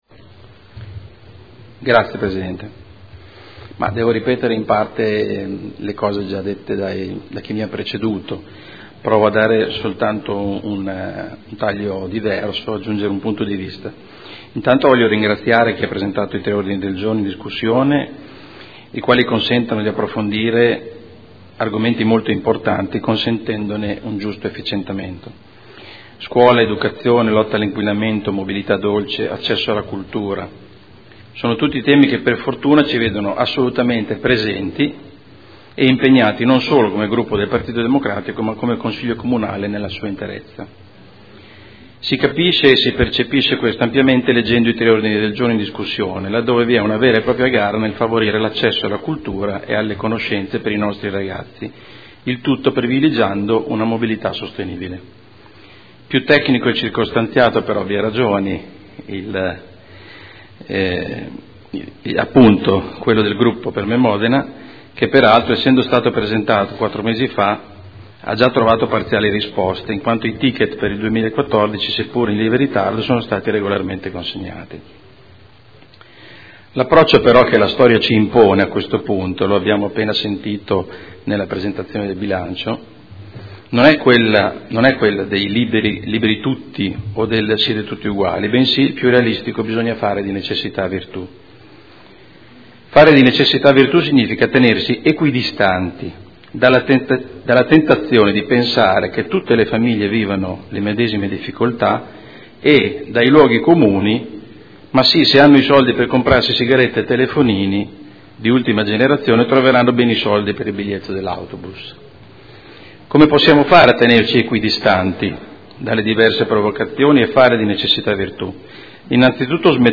Seduta del 09/02/2015. Dibattito sugli ordini del giorno inerenti il trasporto scolastico gratuito o agevolato